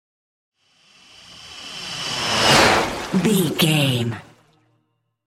Whoosh bright
Sound Effects
bouncy
driving
futuristic
intense
sci fi